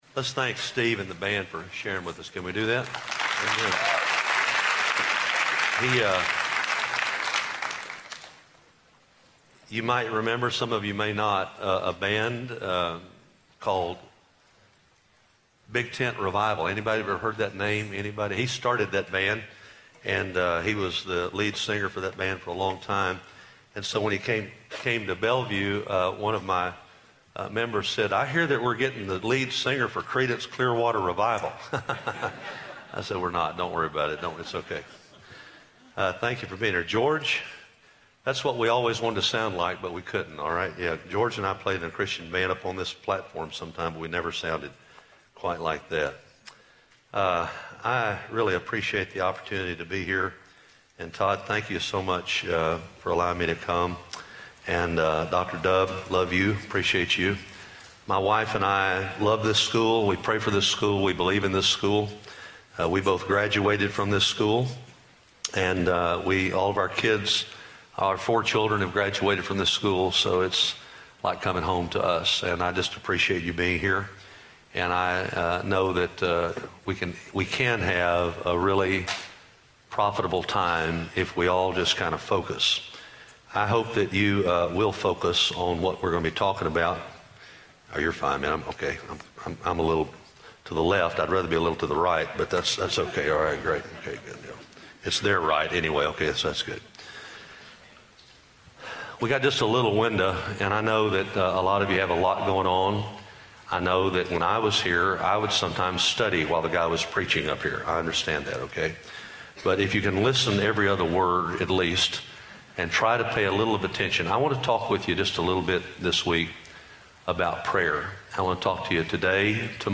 Address: "Why Should We Pray?" from Psalm 116 Recording Date: Oct 14, 2015, 10:00 a.m. Length: 34:46 Format(s): MP3 ; Listen Now Chapels Podcast Subscribe via XML